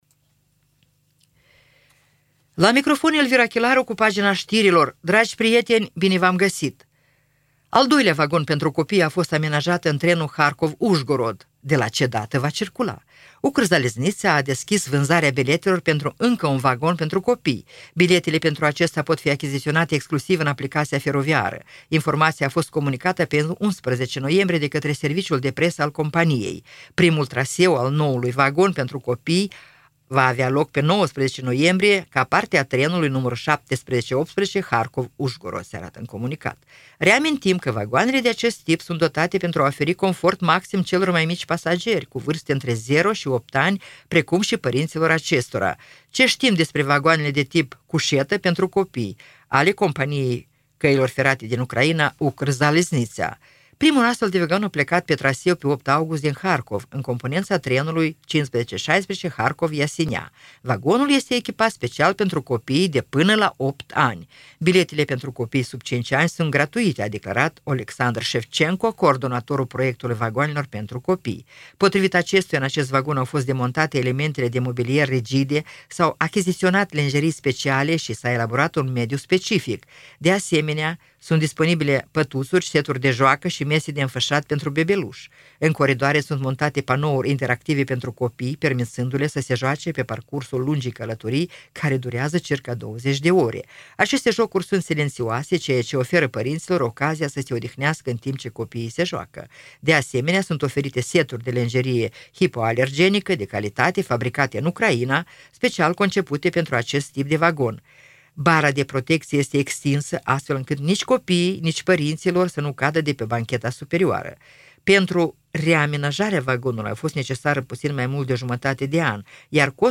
Știri Radio Ujgorod – 12.11.2024, ediția de seară